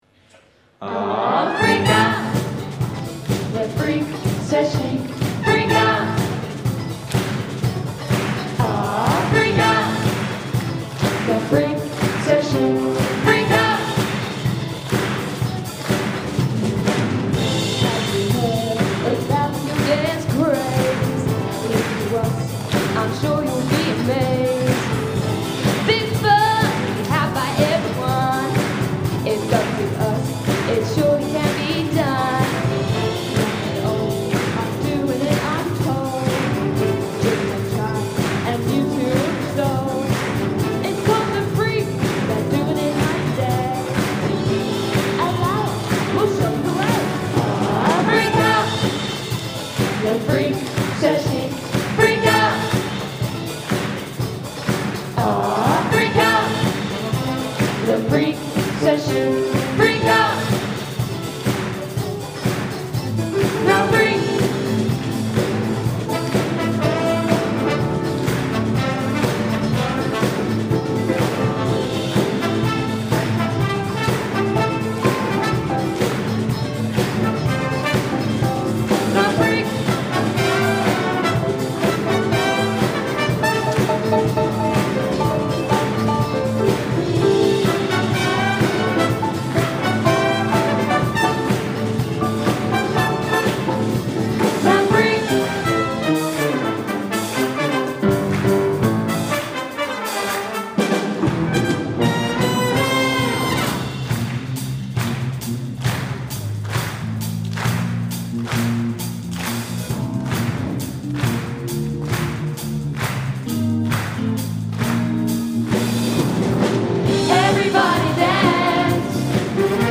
Disco! - Concert Orchestra and Chorus